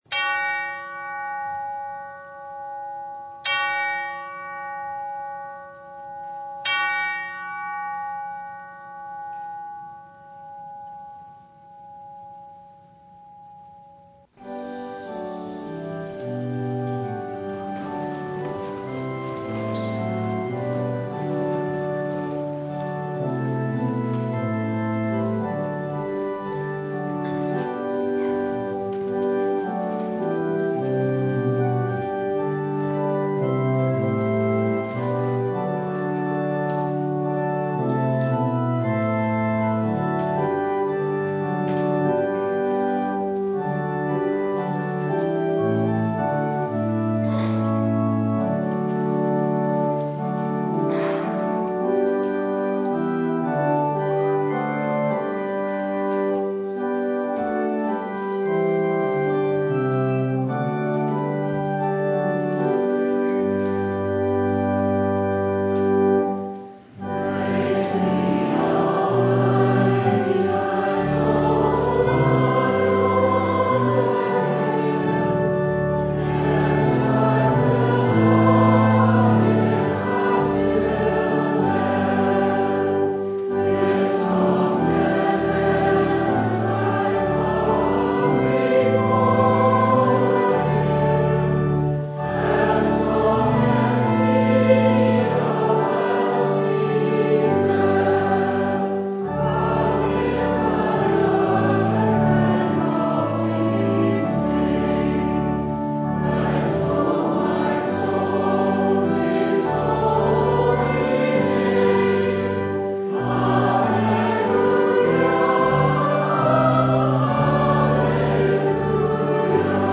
Mark 6:30-44 Service Type: Sunday Even in a desolate place